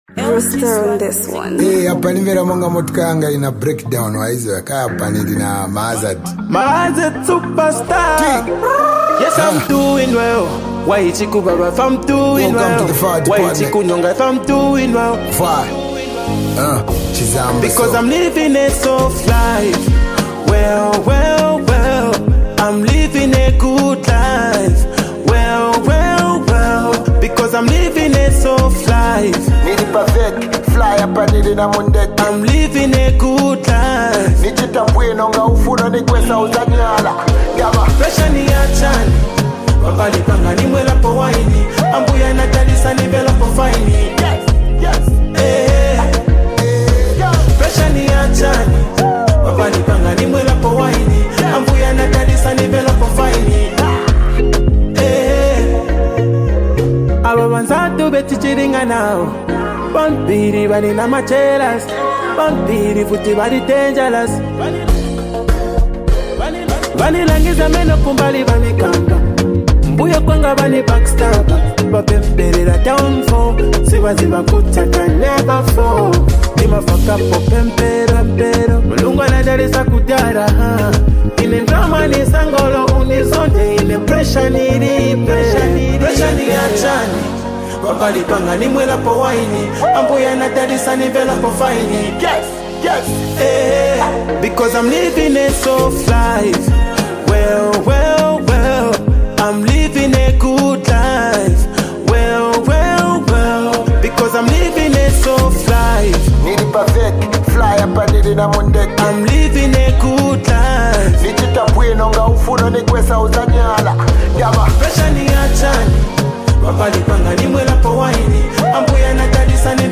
He delivered the bars to the fullest with heavy content.